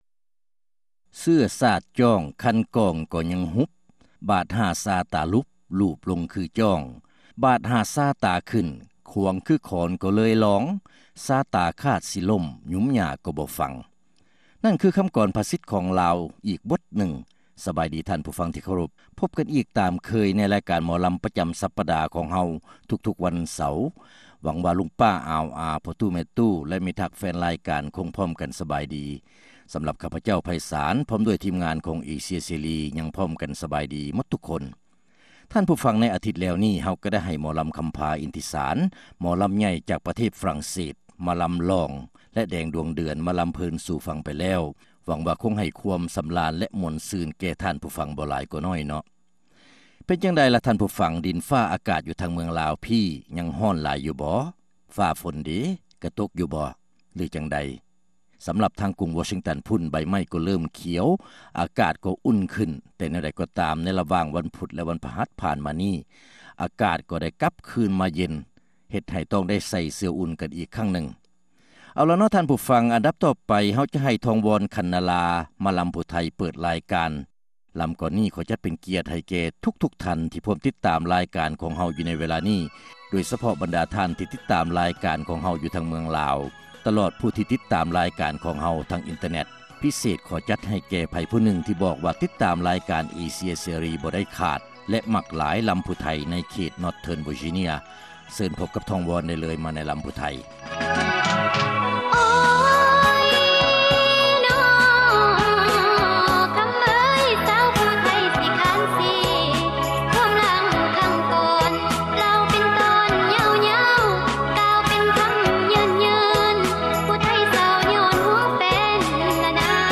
ຣາຍການໜໍລຳ ປະຈຳສັປະດາ ວັນທີ 28 ເດືອນ ເມສາ ປີ 2006